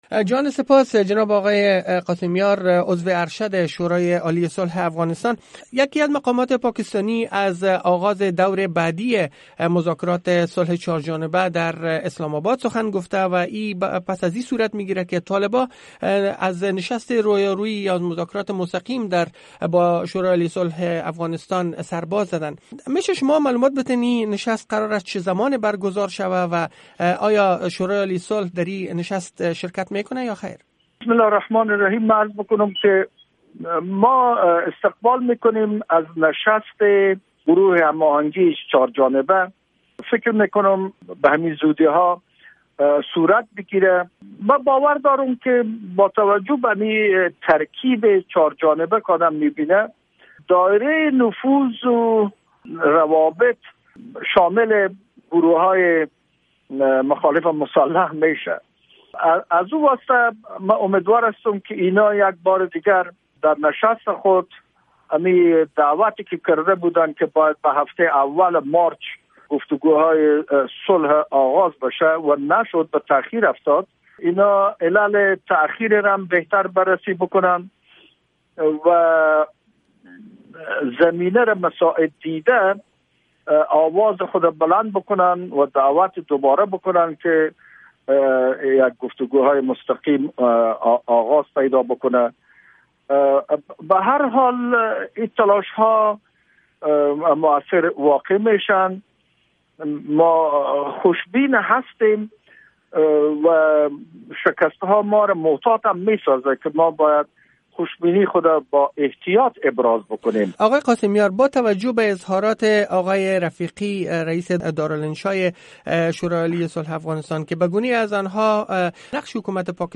مصاحبه ها
اسماعیل قاسمیار، عضو ارشد شورای عالی صلح افغانستان